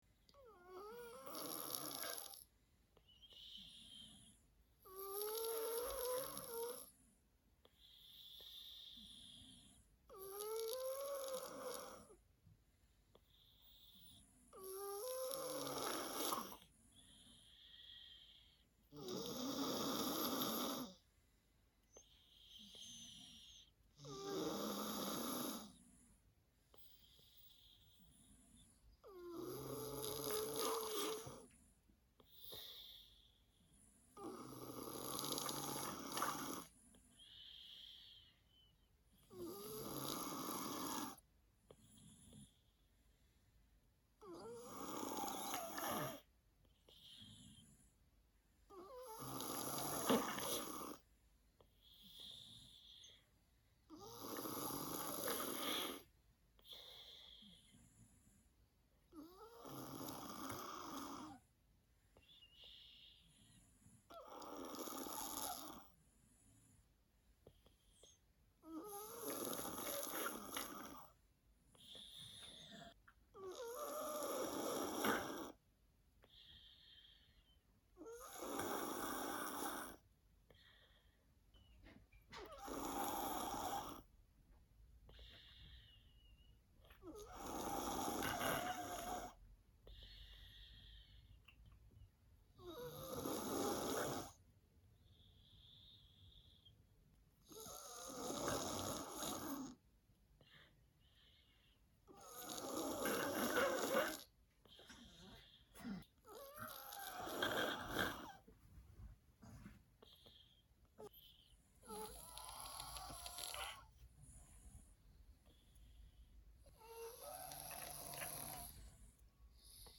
Звуки, где люди спят для монтажа видео в mp3 формате слушать онлайн.
1. Звук спящего человека с насморком
spiachii-chelovek-s-nasmorkom.mp3